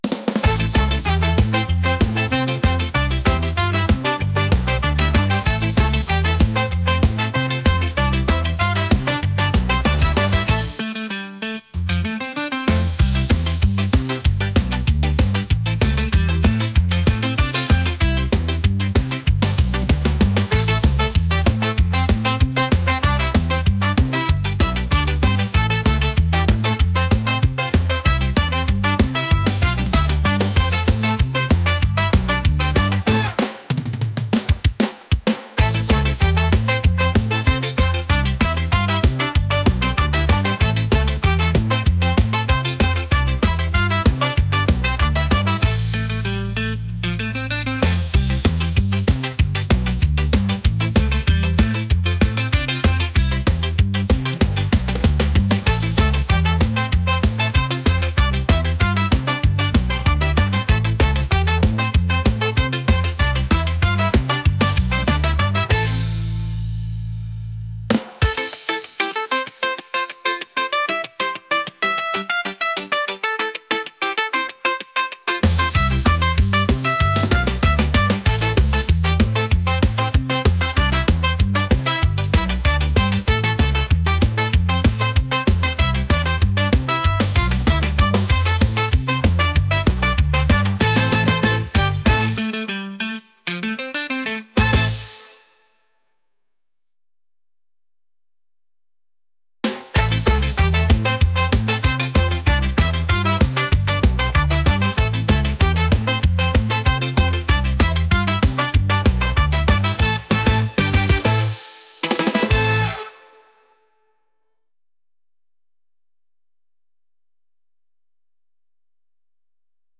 upbeat | ska | energetic